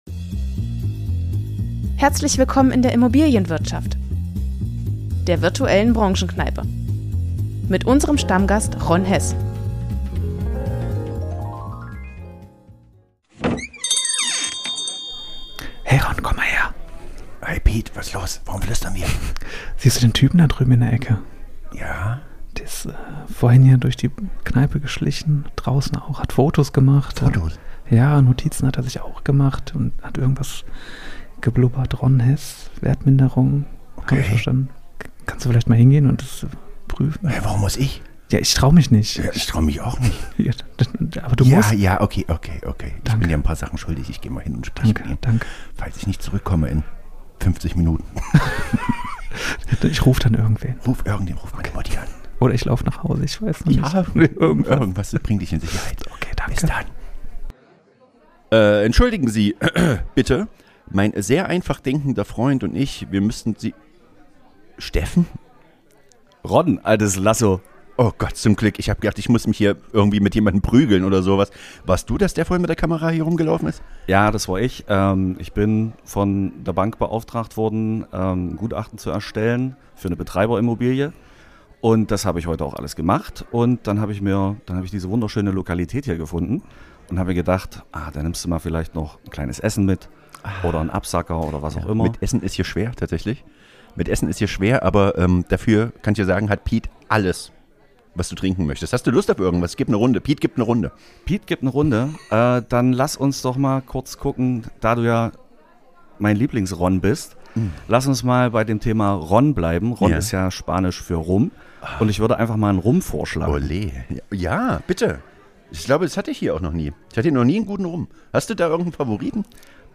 Die Soundeffekte kommen von Pixabay.